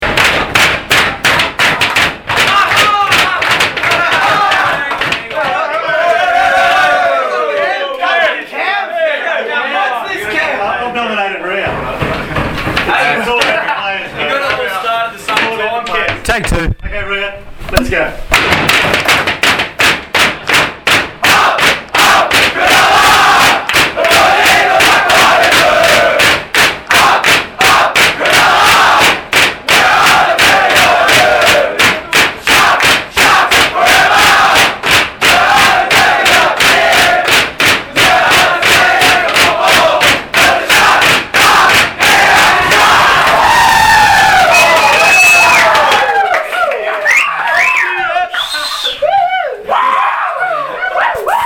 Cronulla Sharks SG Ball team after defeating the Magpies at Shark Park (Photo : OurFootyMedia)
LISTEN TO THE SG BALL TEAM SING UP UP CRONULLA (.mp3) 1 mg
sgBall_sing_upUpCronulla.mp3